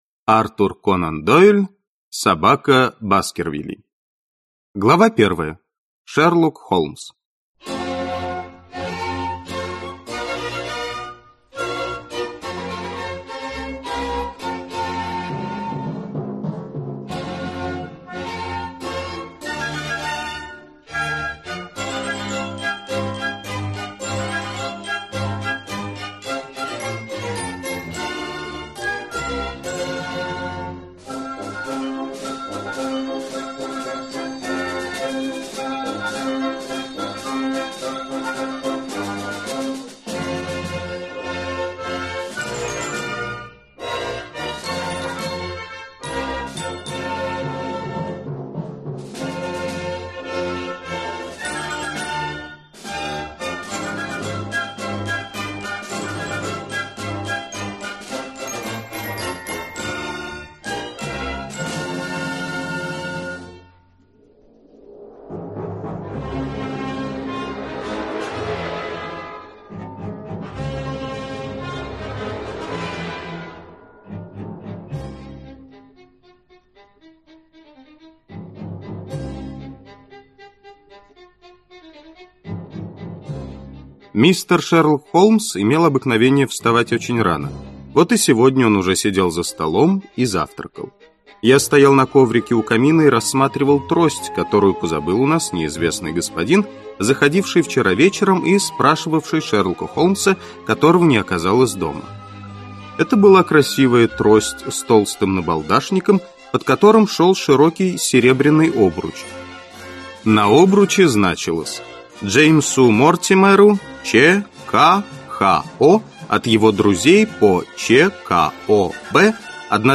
Аудиокнига Собака Баскервилей (с музыкой из фильма) | Библиотека аудиокниг